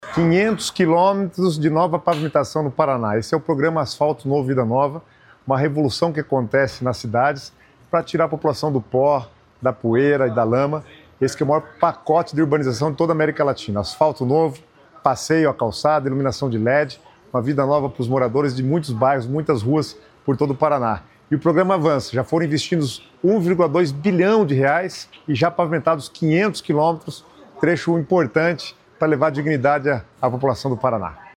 Sonora do secretário das Cidades, Guto Silva, sobre o Asfalto Novo, Vida Nova ter ultrapassado 500 km de pavimentações no Paraná